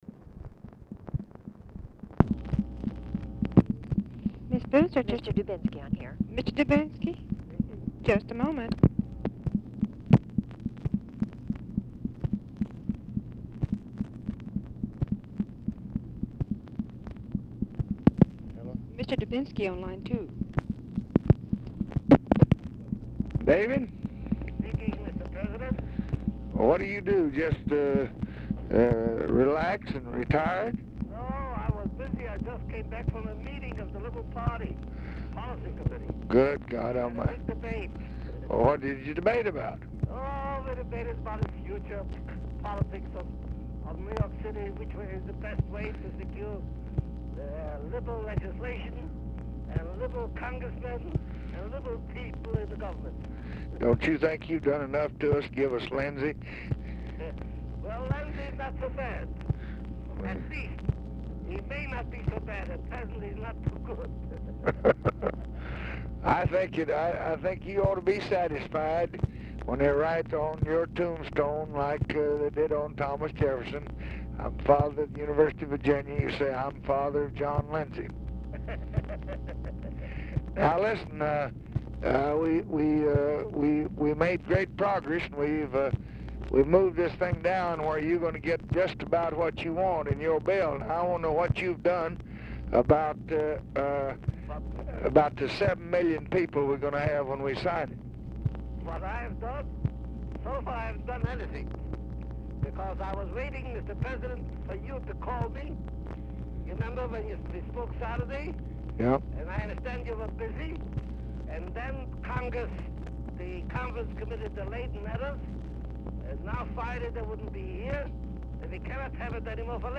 Telephone conversation
DUBINSKY IS DIFFICULT TO HEAR; TV OR RADIO AUDIBLE IN BACKGROUND AT TIMES
Format Dictation belt